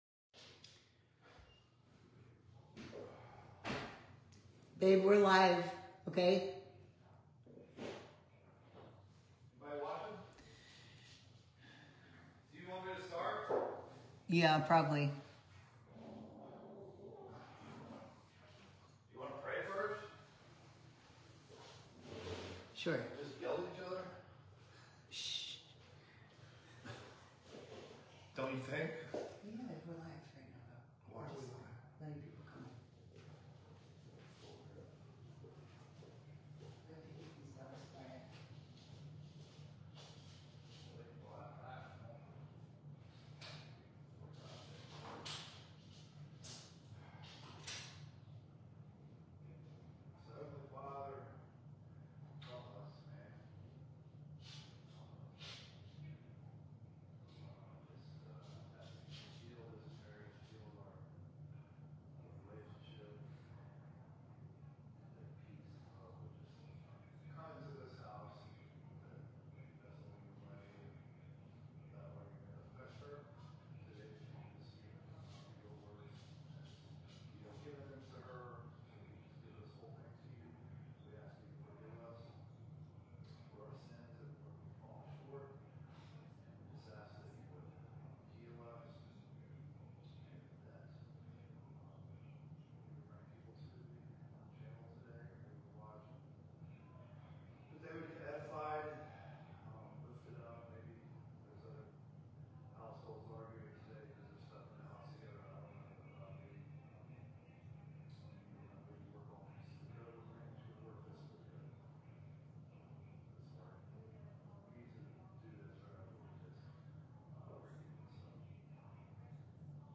Sermons | Serenity Church